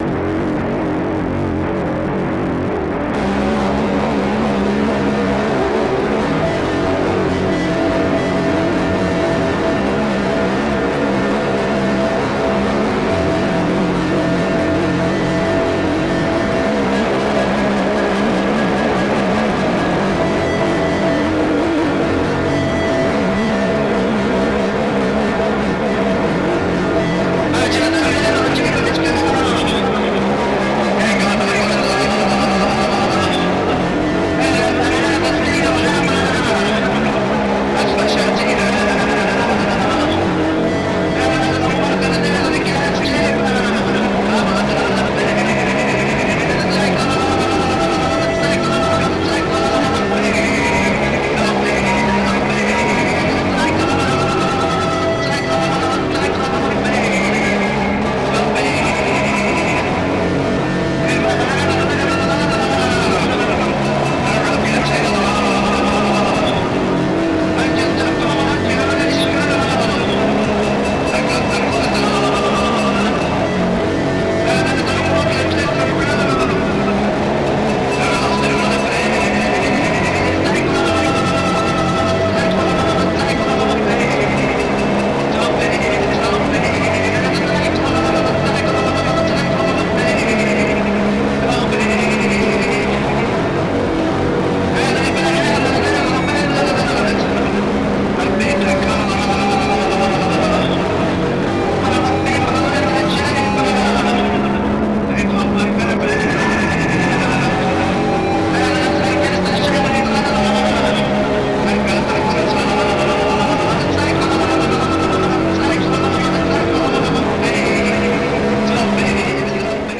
achieves levels of coruscating atonality
• Genre: Industrial / Experimental